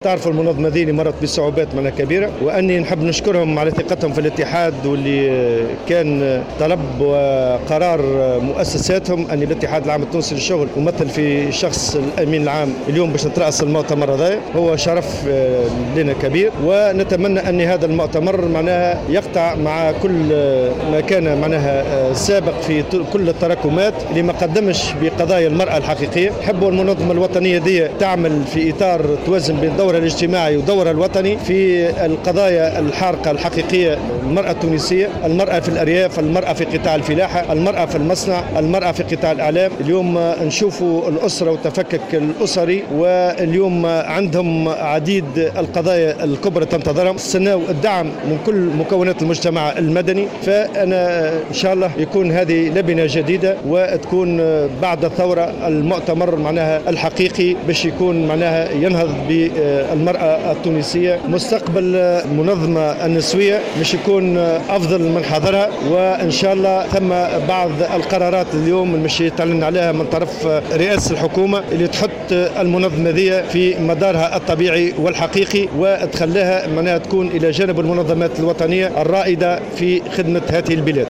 وأضاف الطبوبي في تصريح لمراسلة الجوهرة اف ام، خلال مؤتمر اتحاد المرأة الـ14 الذي ينعقد اليوم في الحمّامات تحت شعر "تونس بنسائها" ويتواصل إلى يوم غد الأحد، أن رئاسة الحكومة ستعلن اليوم عن قرارات هامة لفائدة الاتحاد حتى يتمكن من لعب دوره كمنظمة رائدة في تونس.